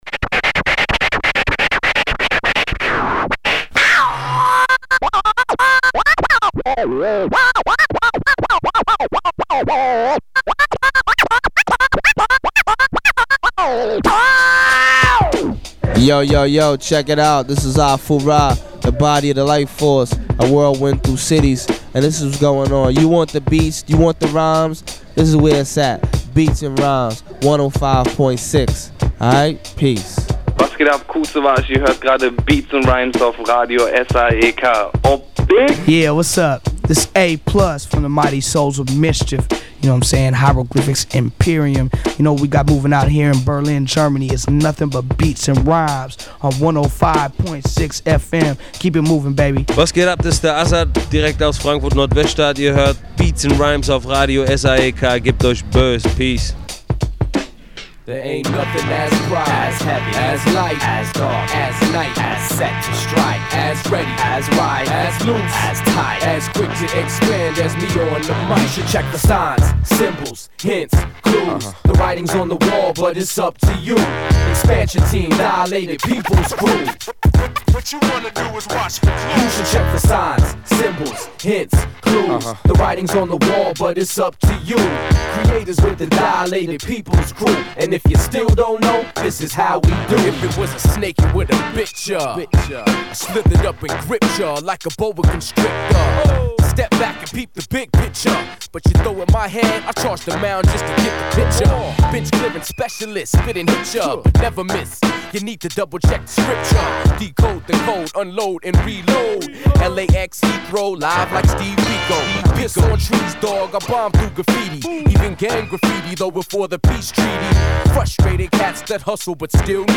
Inhalte: Straight Rapmusic in the Mix